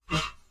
mobs_bunny.ogg